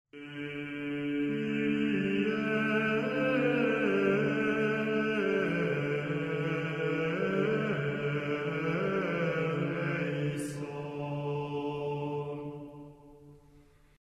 bourdon0-2.mp3